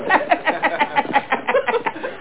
(NZ) 2001 May / PCW0501.iso / games / wgolfsez.exe / WiniGolf.exe / 0 / WAVE / LAUGH ( .mp3 ) < prev next > Waveform Audio File Format | 2000-10-08 | 18KB | 1 channel | 8,000 sample rate | 2 seconds
LAUGH.mp3